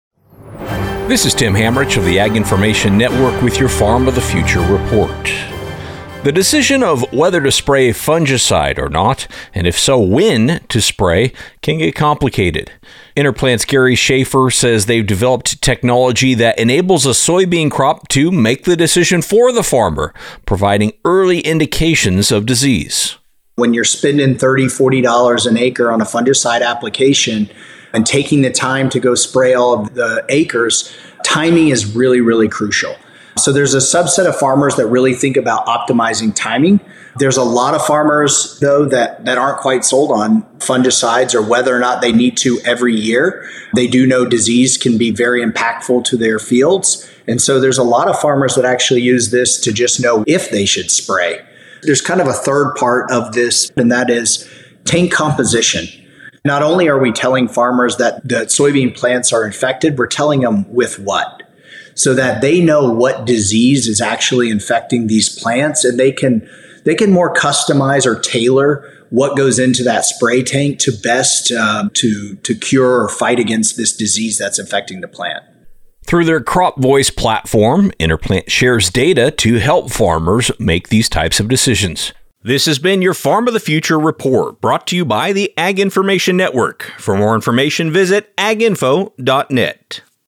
News Reporter